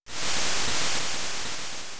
Shore.wav